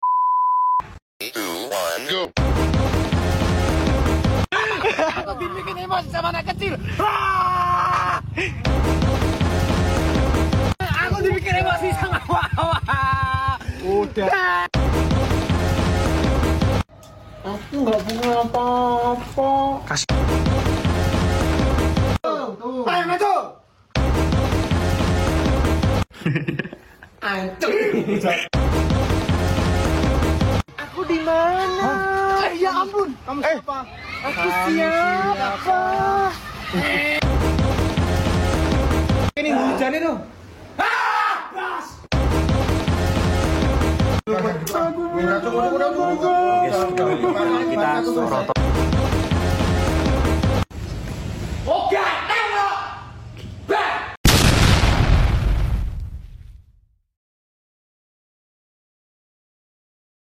suaranya kasian bangett🥹 tapi lucuuuuukkk